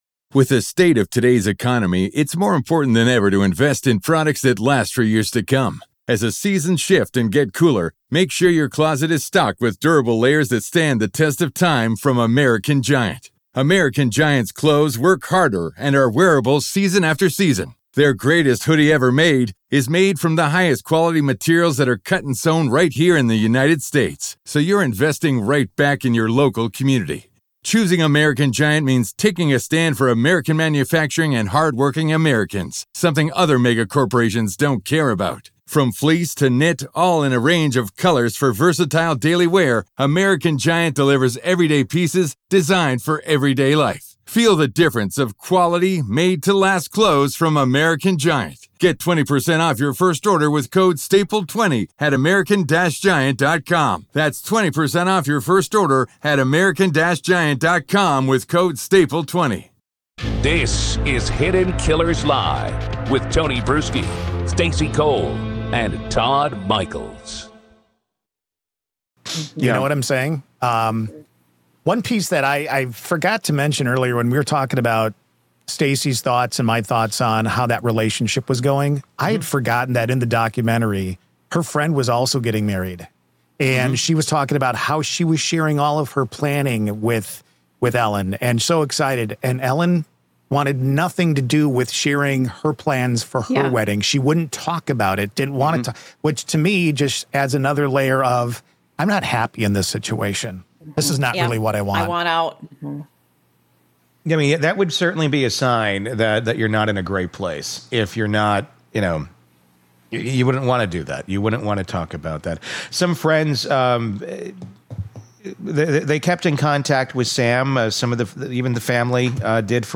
Hidden Killers Live